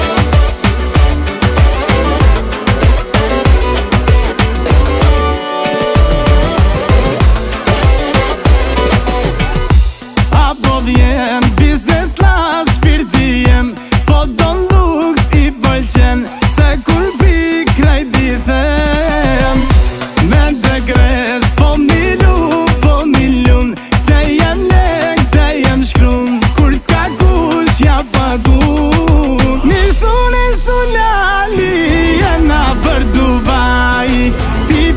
Жанр: Поп музыка / Альтернатива
Indie Pop, Alternative